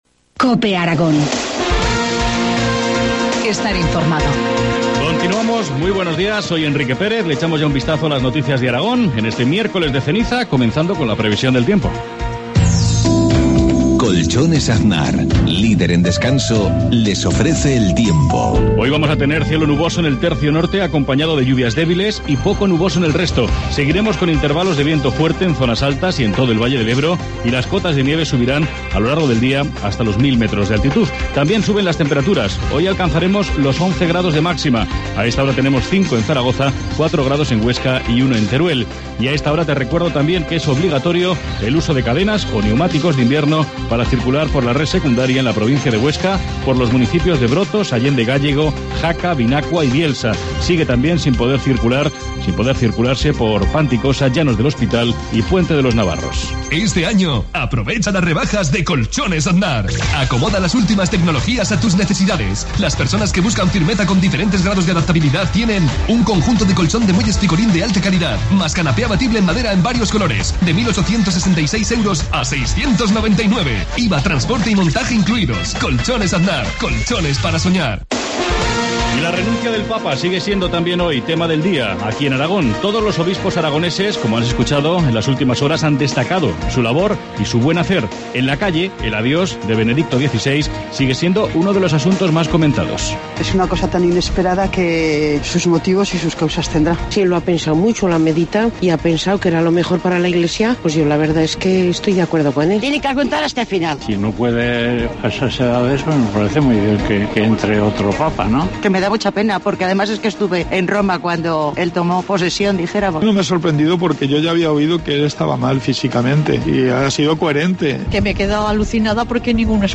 Informativo matinal, miércoles 13 de febrero, 7.53 horas